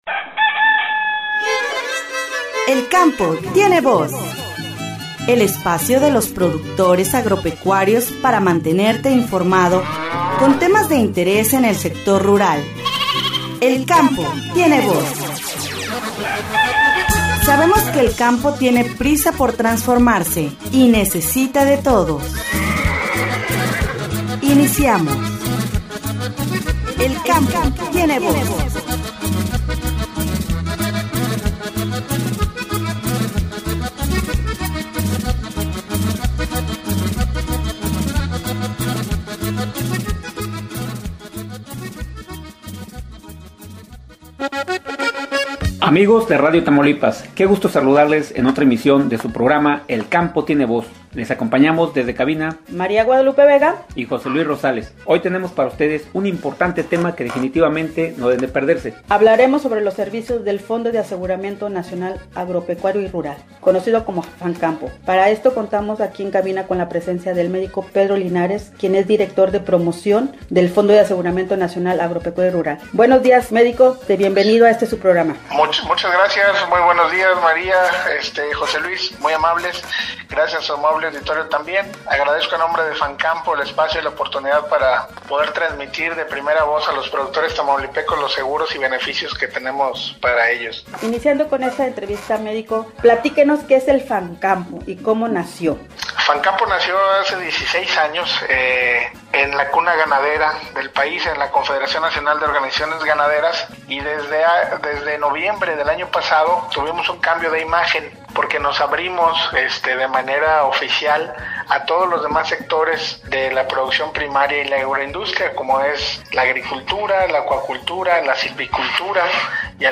Escúchanos todos los miércoles a las 08:30 de la mañana es esta su estación Radio Tamaulipas.